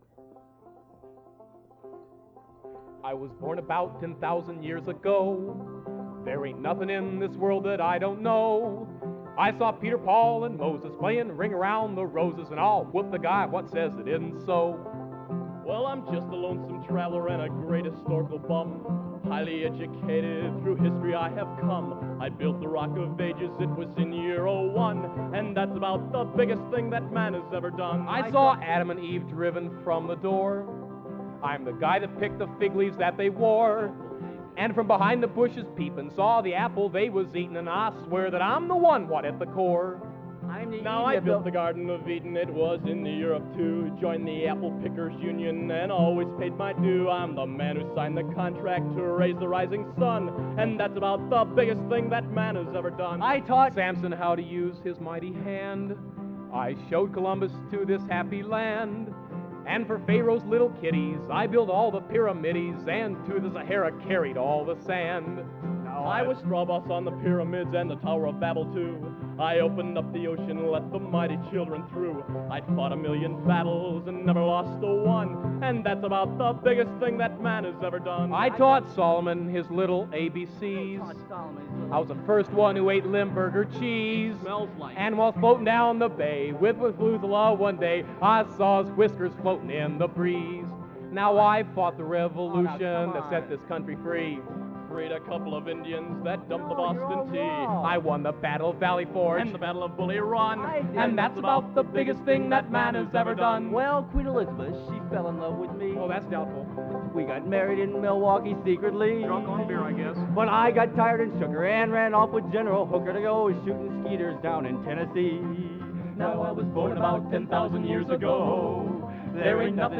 Genre: Traditional | Type: End of Season |Specialty